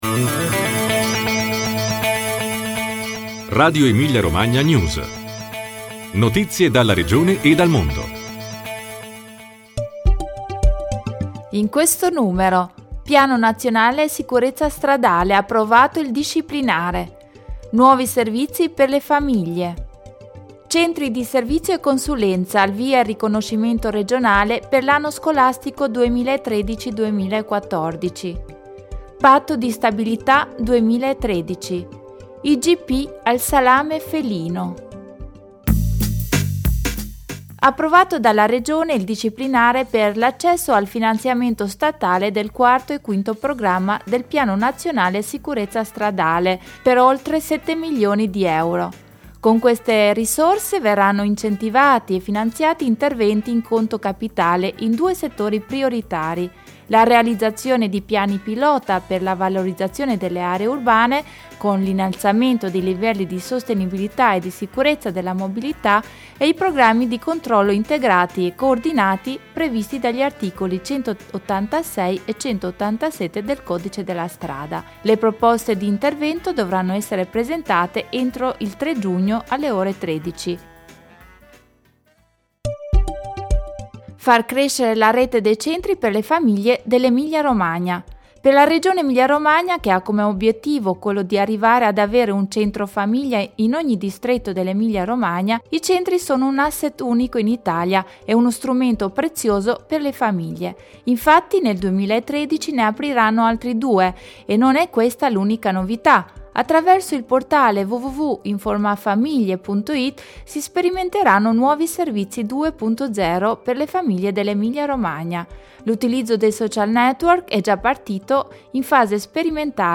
Notizie dalla Regione e dal mondo